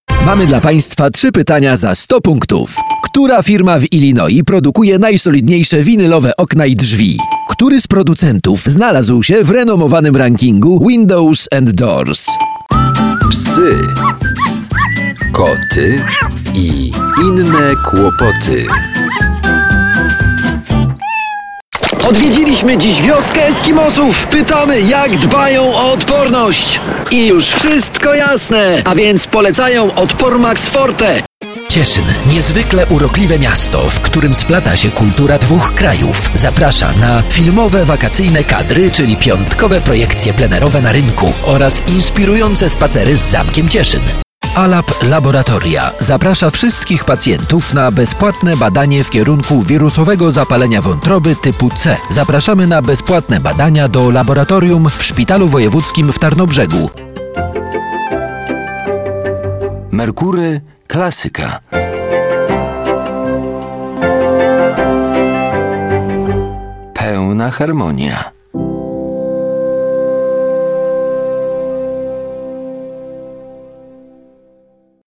Male 30-50 lat
A voice with a low, sandy timbre, constantly seeking new challenges.
Spot reklamowy